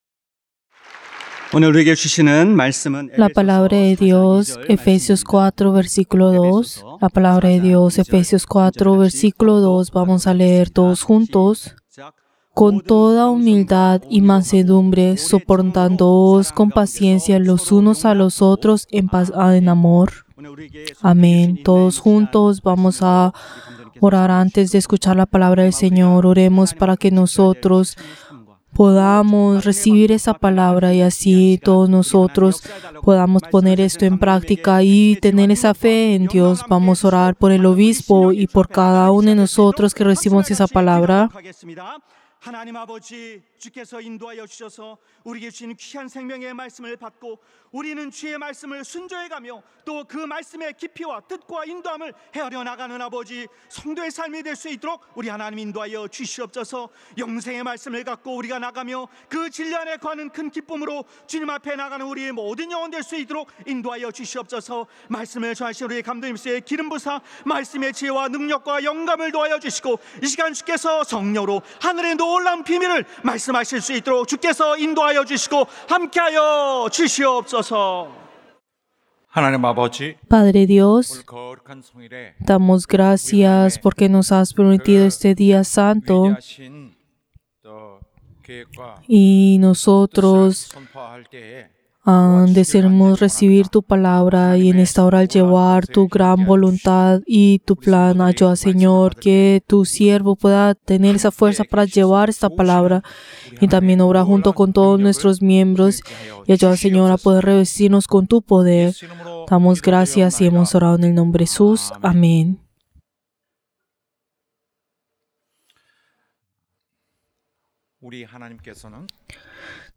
Servicio del Día del Señor del 3 de marzo del 2024